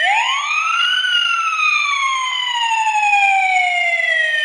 警报器/sirens " 哭声警报2 WAV
描述：短暂的嚎叫警报。
标签： 哀号 警报器 报警器
声道立体声